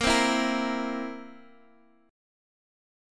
Bbsus2b5 chord